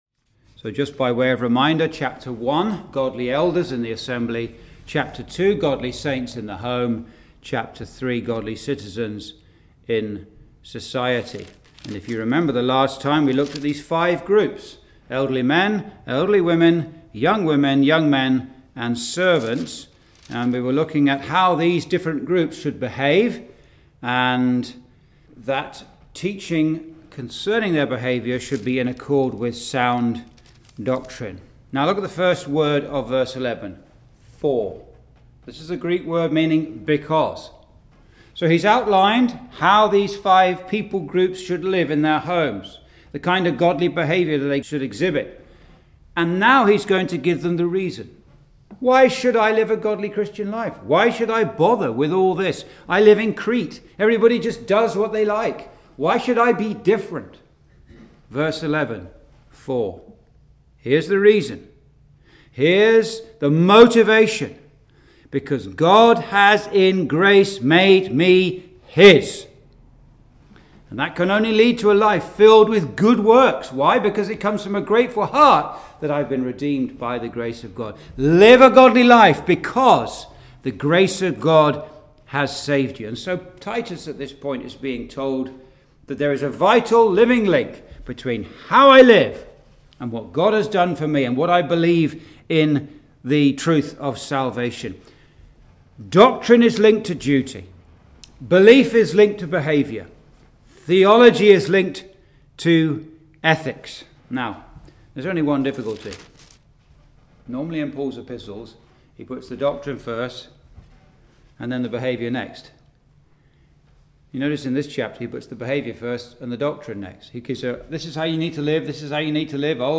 (Message preached in Chalfont St Peter Gospel Hall, 2022)
Verse by Verse Exposition